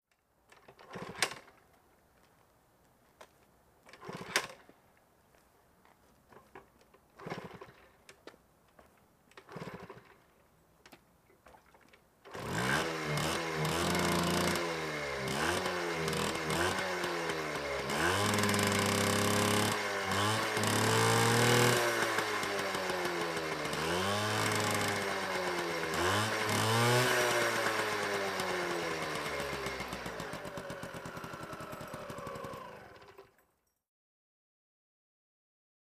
Motor Scooter, False Starts, Start Medium Cu, Revs, Idle, Off.